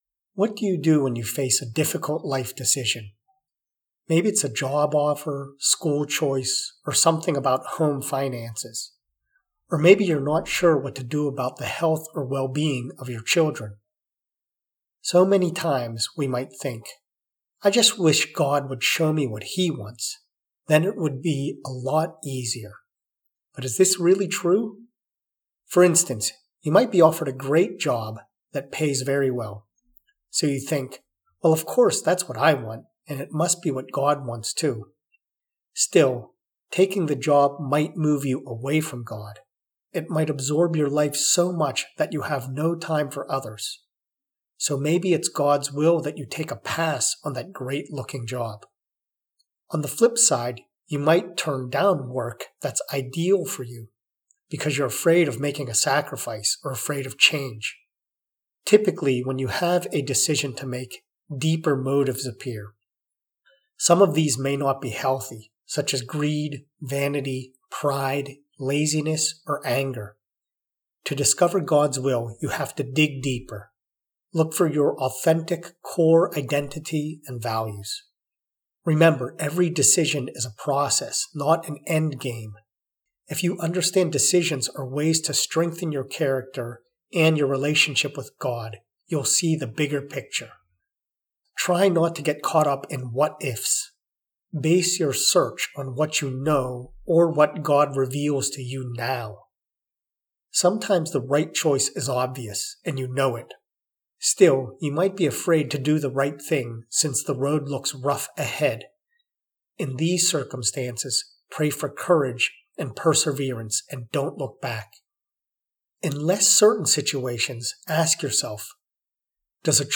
prayer-for-gods-will-in-my-life.mp3